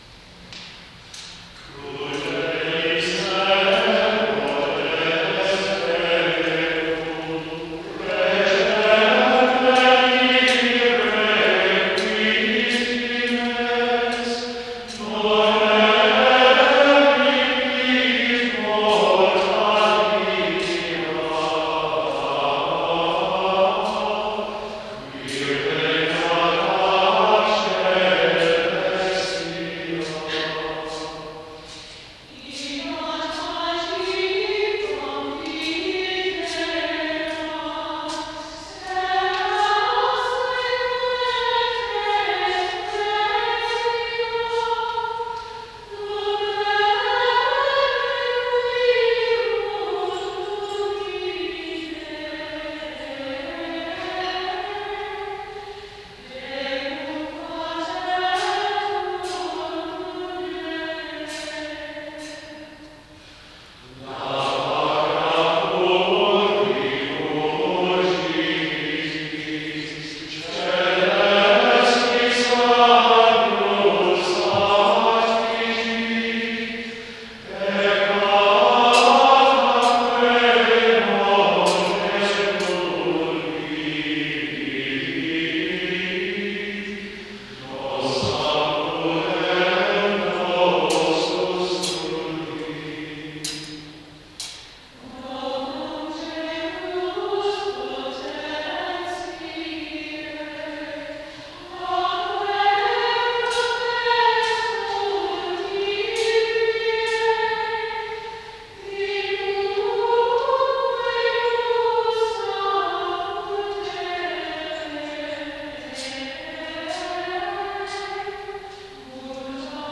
07-hymn-crudelis-herodes.mp3